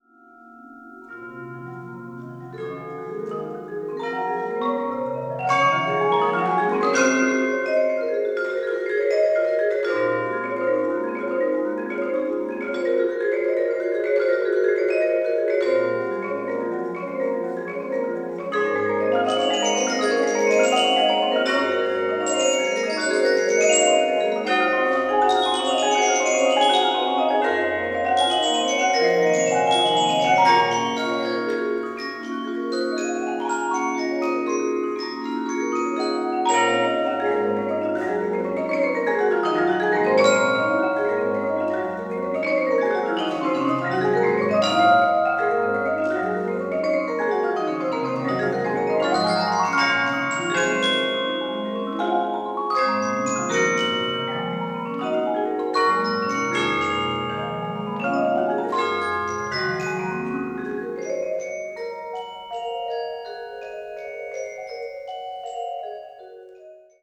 Tetrahedral Ambisonic Microphone
Recorded February 23, 2010, in the Bates Recital Hall at the Butler School of Music of the University of Texas at Austin.